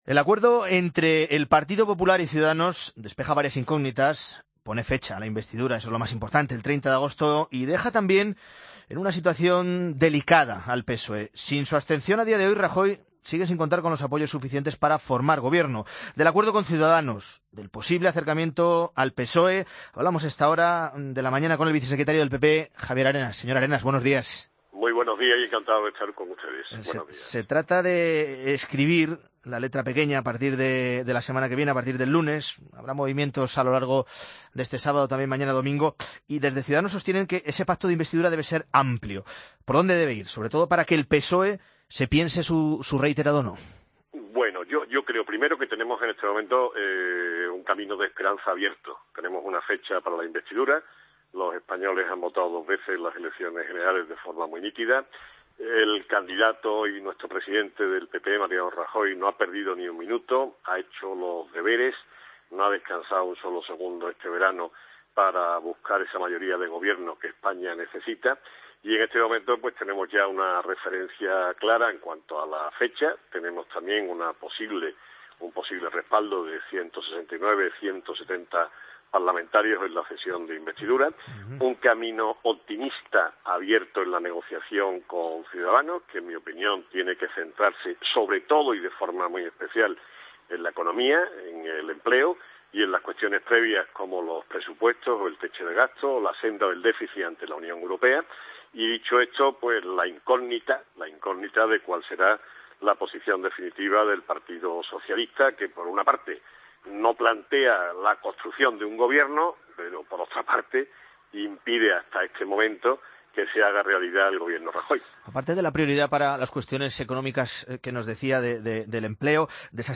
AUDIO: Escucha la entrevista a Javier Arenas, vicesecretario general del PP, en La Mañana de Fin de Semana